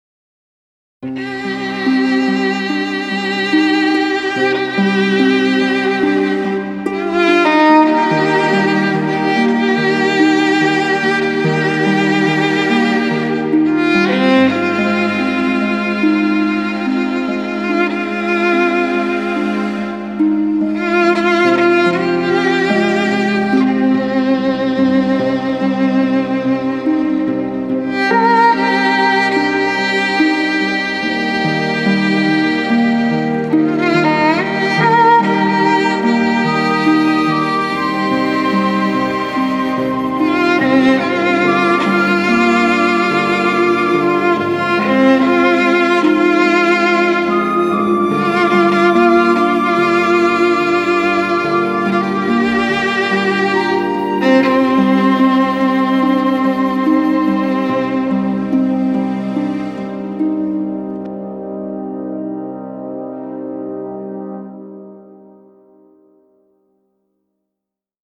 ژانر: بی کلام